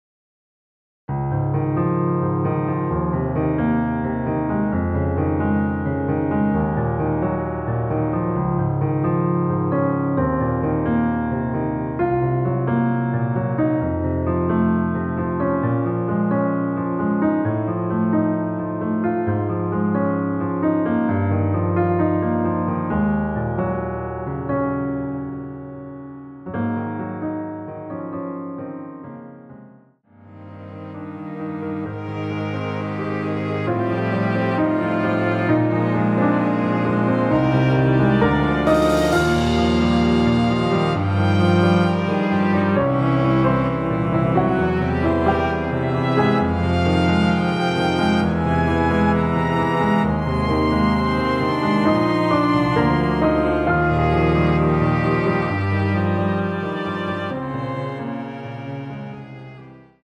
원키에서(-3)내린 MR입니다.
Db
앞부분30초, 뒷부분30초씩 편집해서 올려 드리고 있습니다.
중간에 음이 끈어지고 다시 나오는 이유는